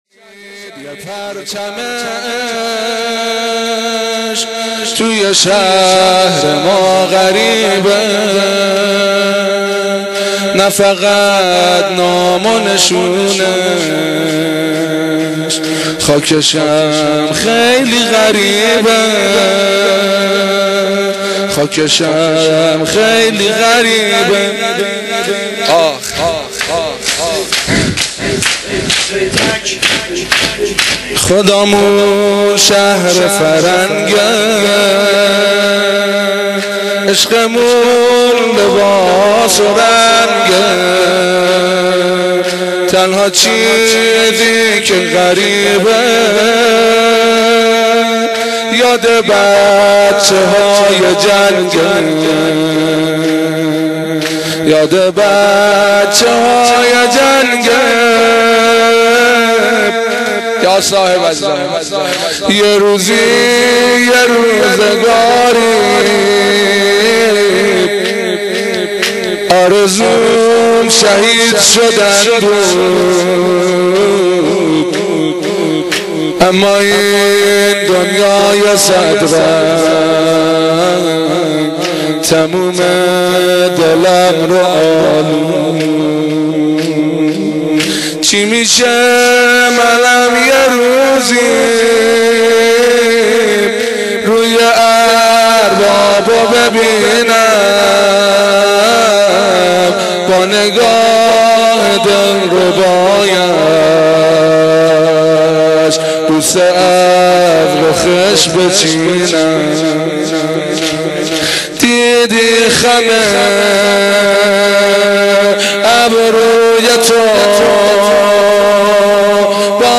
مداحی شور شهدایی
• شور شهدایی